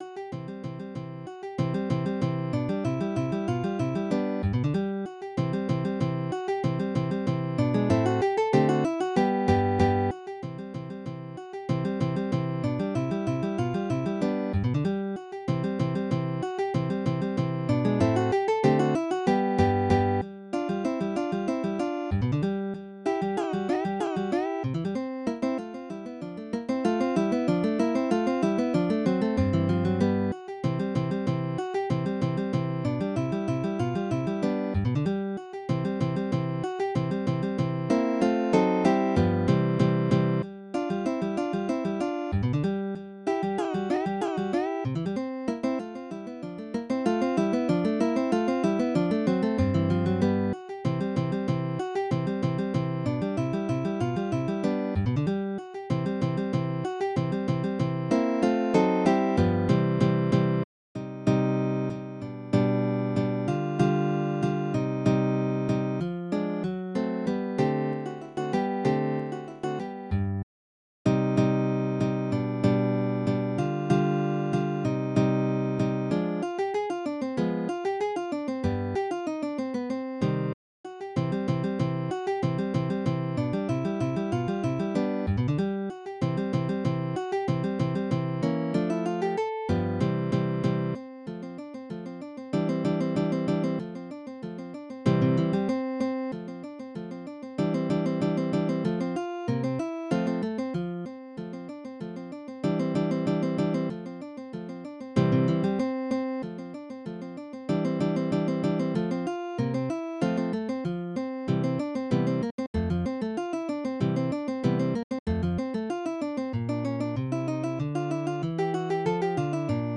Midi音楽が聴けます 3 220円